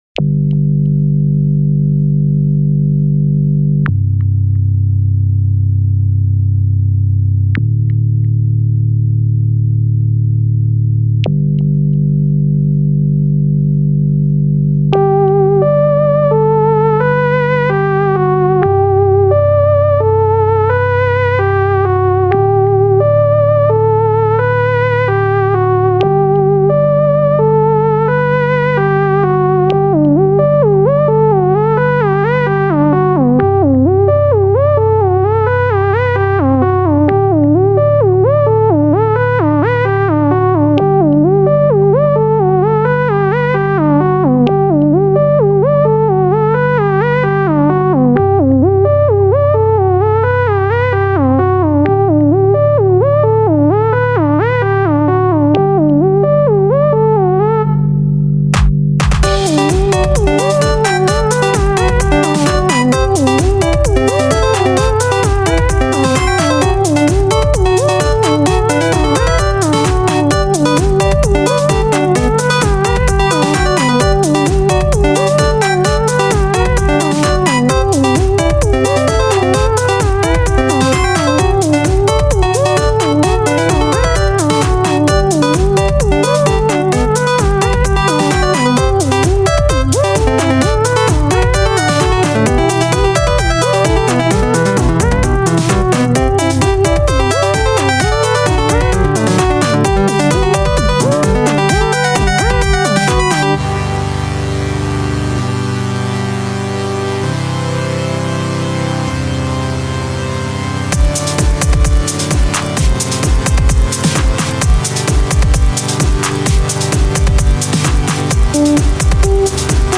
I didnt boost the bass enough.
Used with FL Studio 10